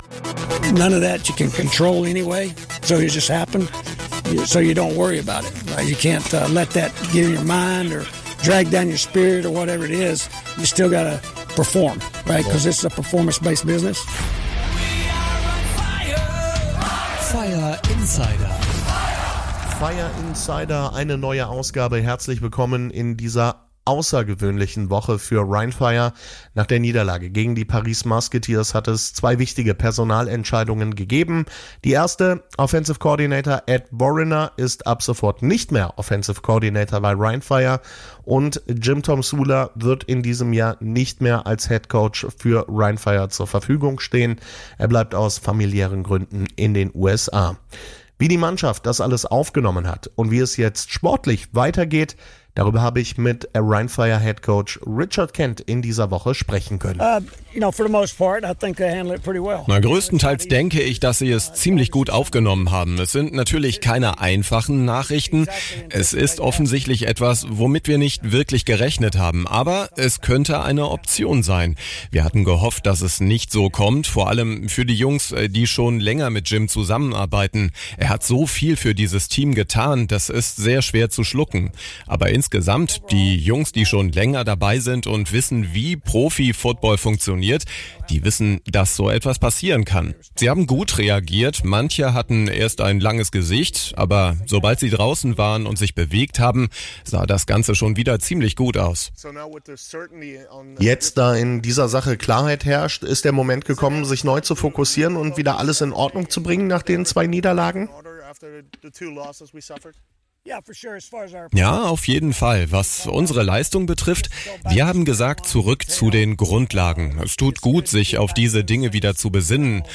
Im Gespräch gibt er einen Einblick, wie viel Arbeit der Support für ein Football-Team bedeutet – ein Support, den alle im Staff gerne leisten.